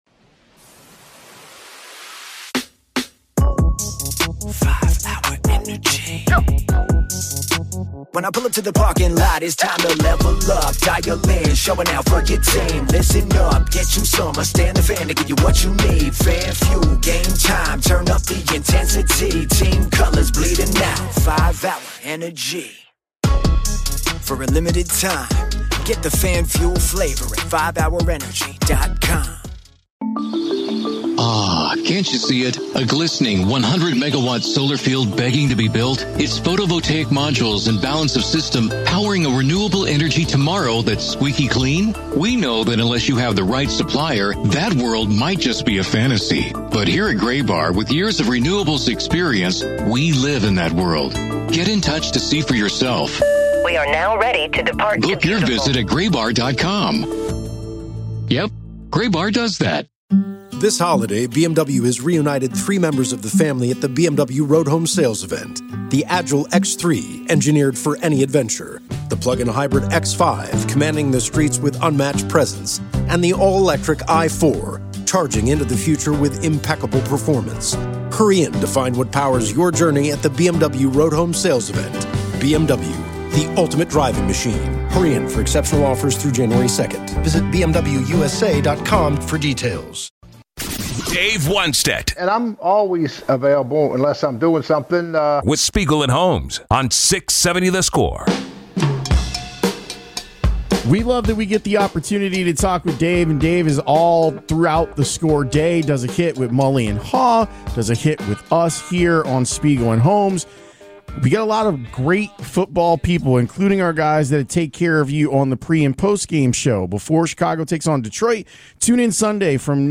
Dave Wannstedt shares a story about former MLB manager Jim Leyland (; 20 Dec 2024) | Padverb